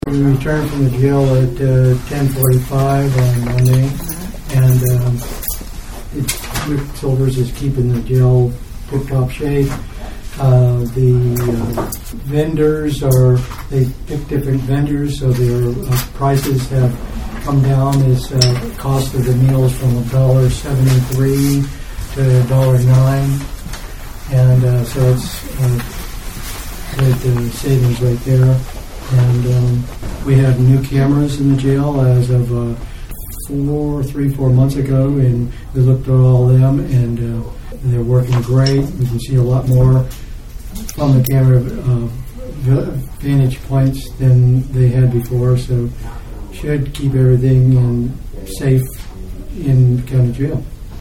Commissioner Mike Bouvier says that the commissioners were impressed with how the jail is operated.